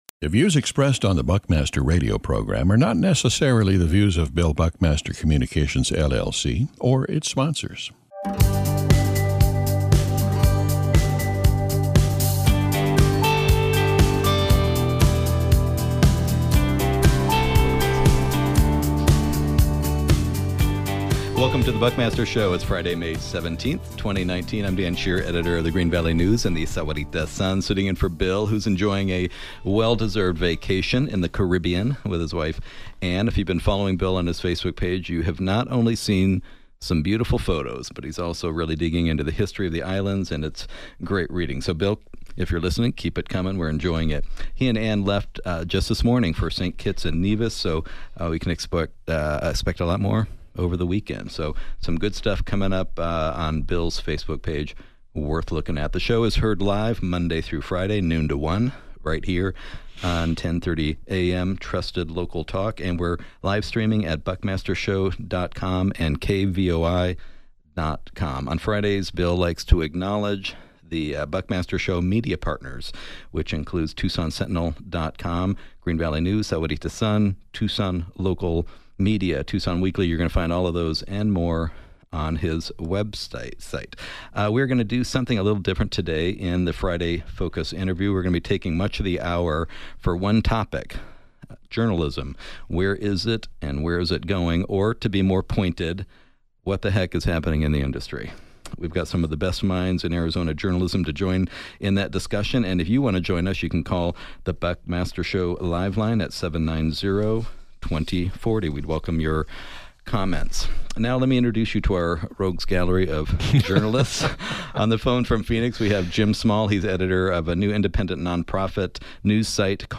The Friday Focus interview welcomes several Arizona journalists to discuss where the industry is and where it’s going.